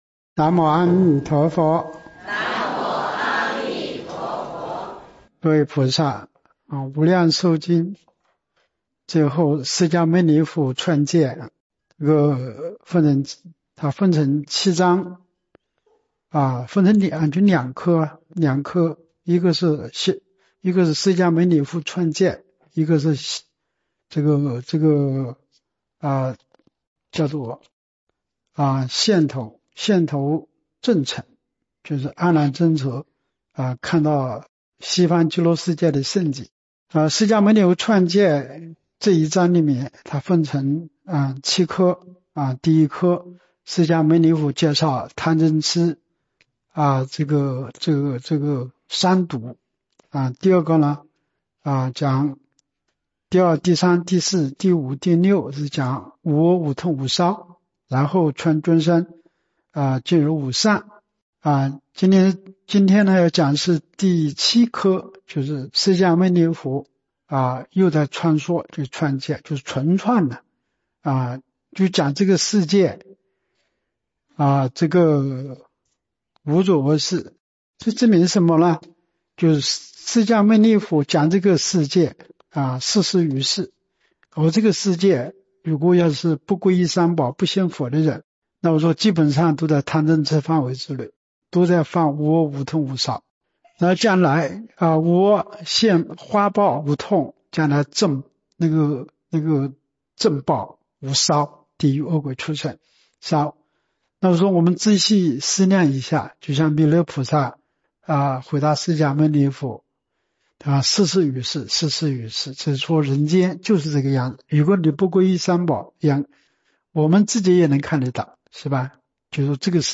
（广佛寺）